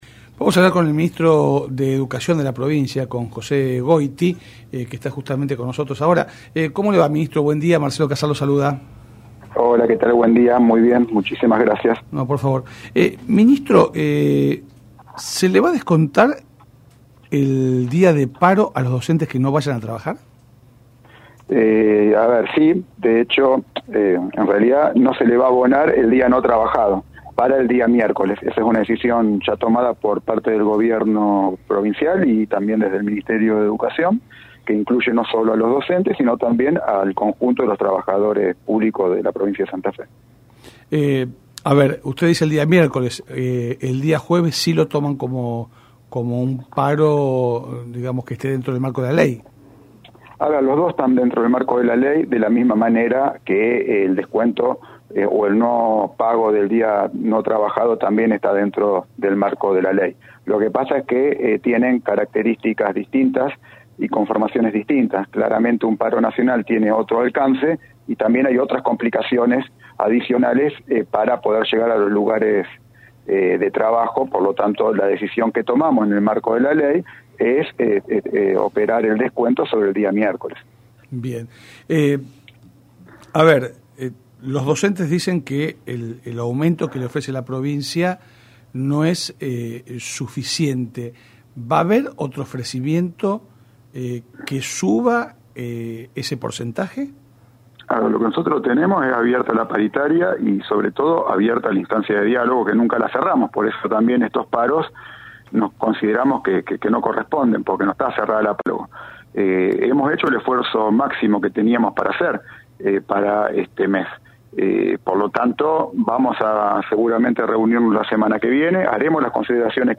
El ministro de Educación de Santa Fe, José Goity, confirmó en contacto con el programa La barra de Casal que el docente que no concurra a trabajar por el paro del miércoles 8 no cobrará el día y además perderá el premio a la asistencia perfecta por este mes.